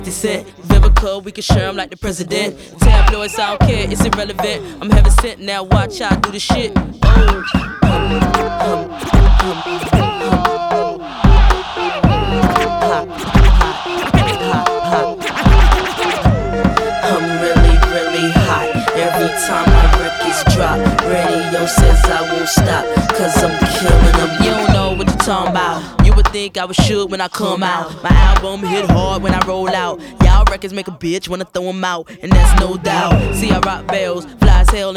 Жанр: Хип-Хоп / Рэп / R&B / Альтернатива / Соул